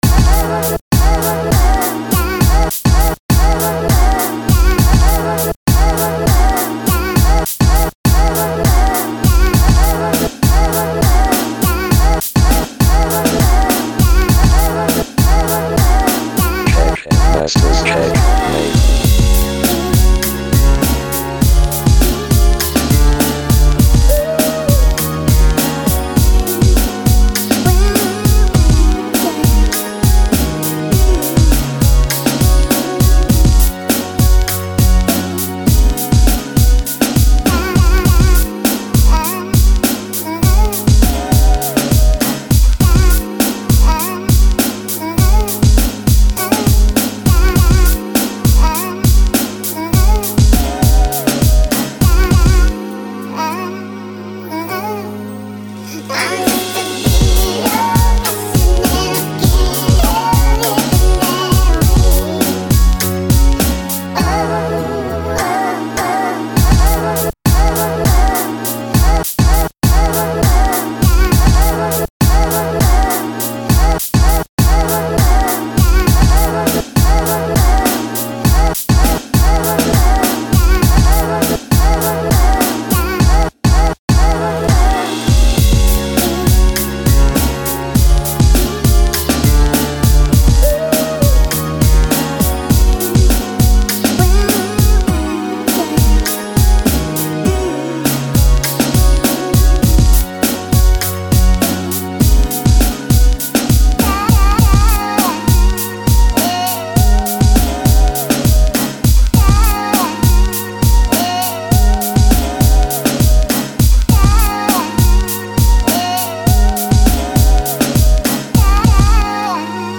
샘플링 한 열곡 했으니 그만하고 얼른 시퀀싱 잡아야죠 ㅋ
의도적으로 변주가 많은 컨셉으로 만든 곡입니다.